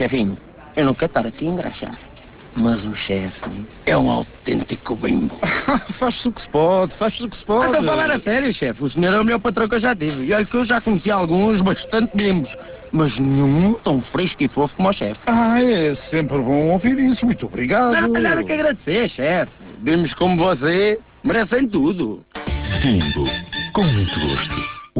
Estreou dia 1 de Julho uma campanha da marca Bimbo, são 5 novos spots de 25 e 30 segundos que passaram 76 vezes na RFM desde a estreia do spot e até ao dia 14 de Julho onde foi investido pela marca 38 832 euros, a preço tabela (